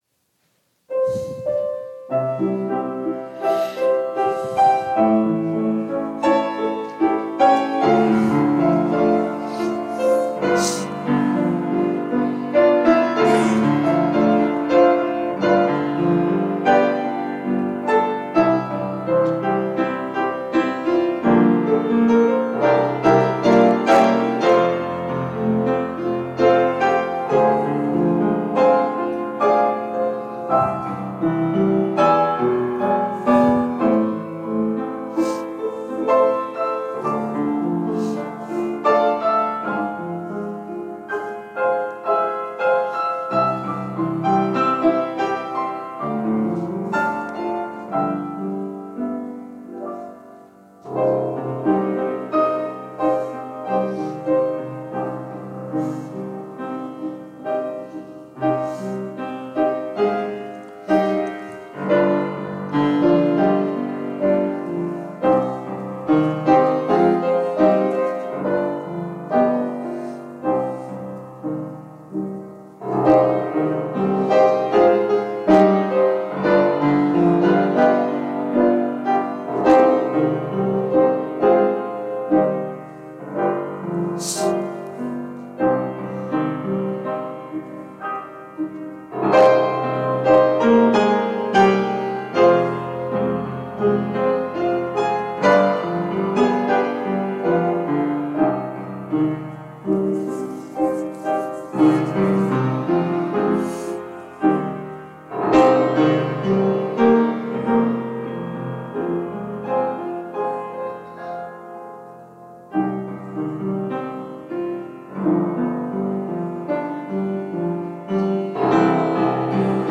Individual non-series sermons